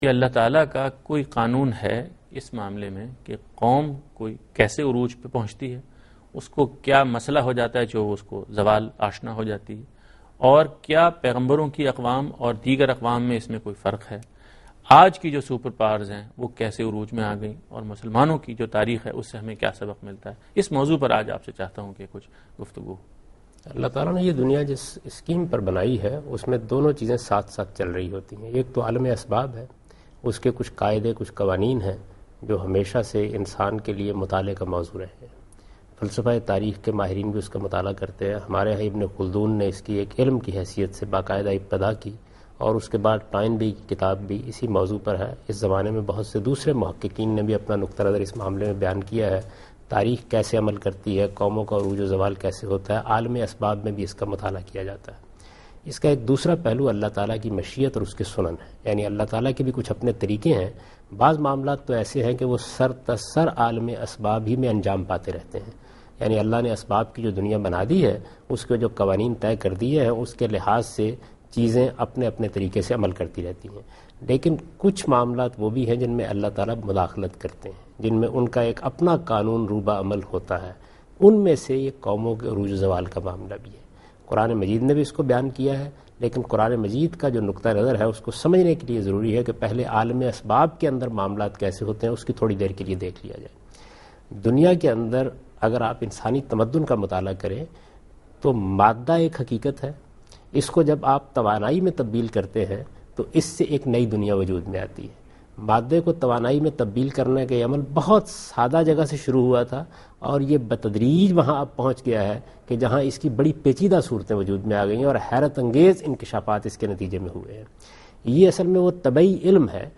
Category: TV Programs / Dunya News / Deen-o-Daanish /
Answer to a Question by Javed Ahmad Ghamidi during a talk show "Deen o Danish" on Dunya News TV